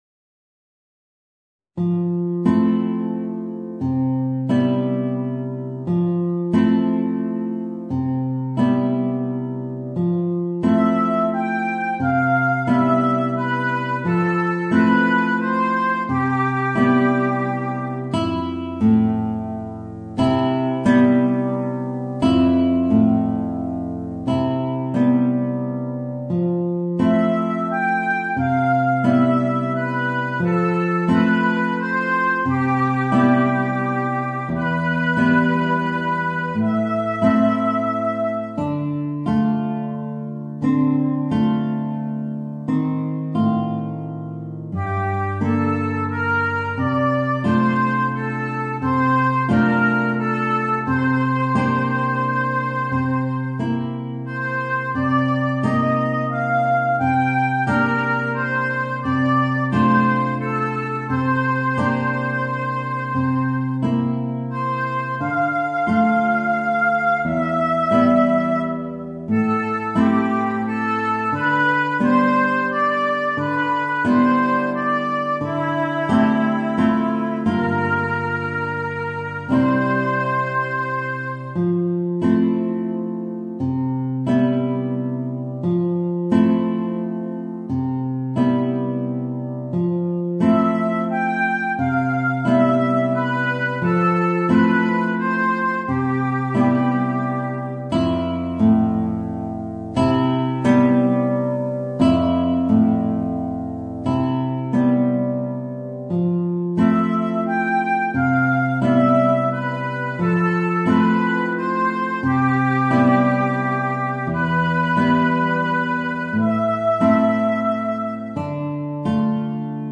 Voicing: Guitar and Oboe